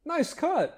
Add voiced sfx
nicecut2.ogg